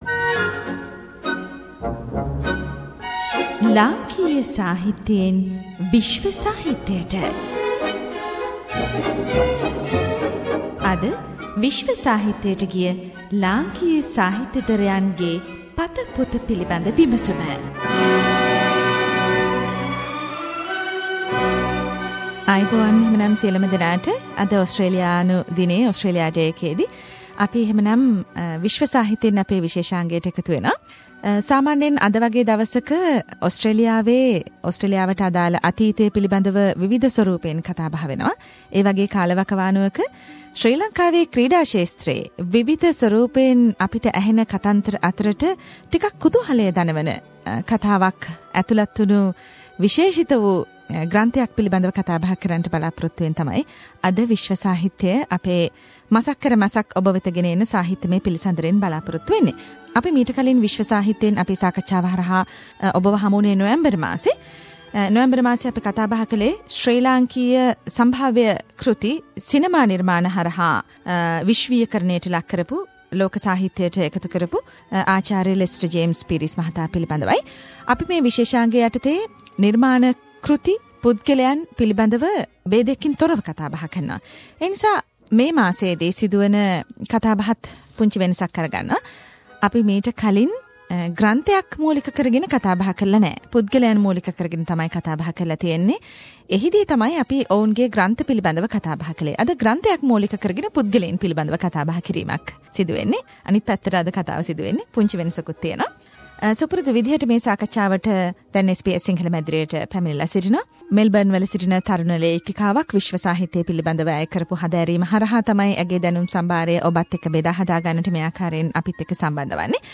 “SBS Sinhala” monthly discussion forum of world literature - Chinaman: The Legend of Pradeep Mathew